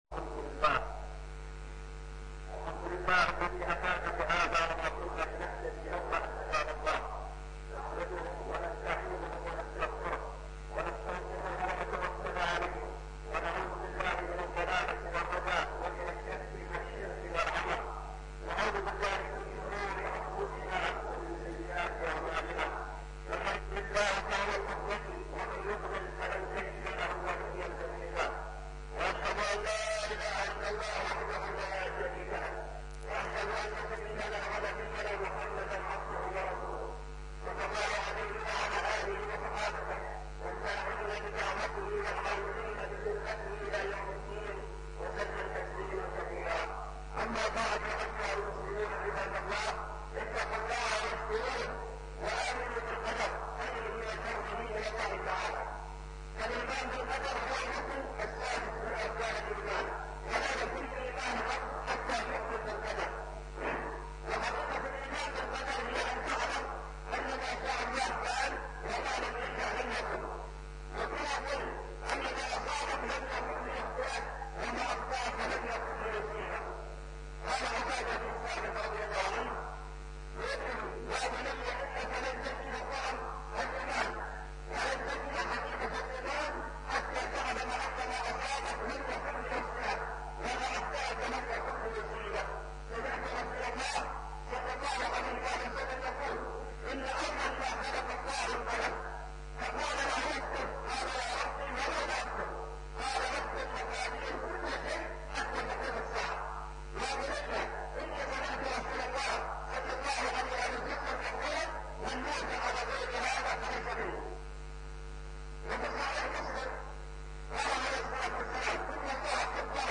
(خطبة جمعة) بعنوان (الإيمان بالقدر) والثانية (العصبيات الجاهلية)